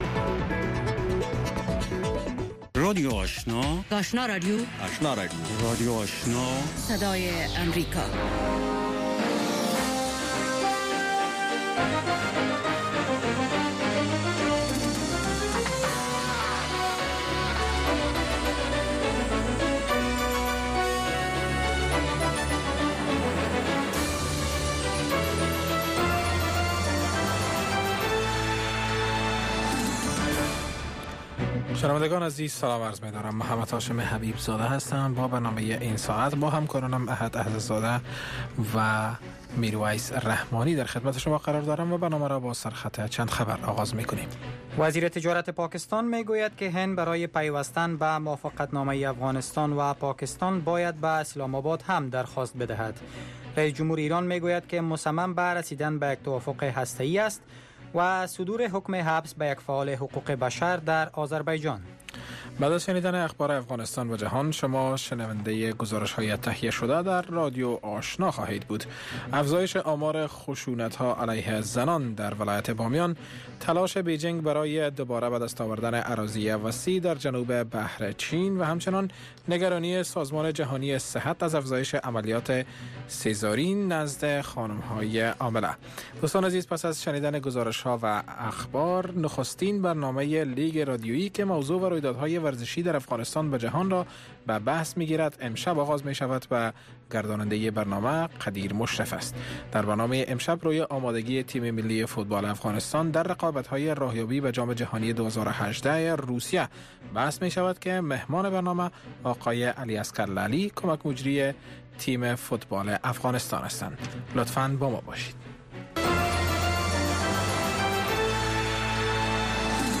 برنامه گفت و شنود/خبری اتری - گفتمان مشترک شما با آگاهان، مقام ها و کارشناس ها.